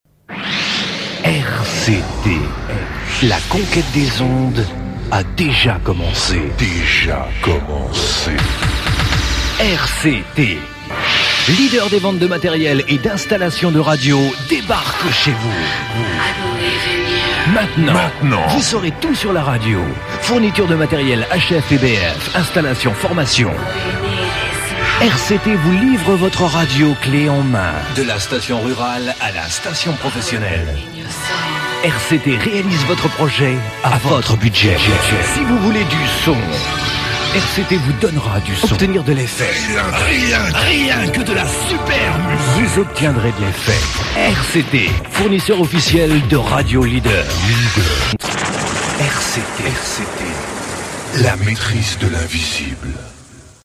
spot publicitaire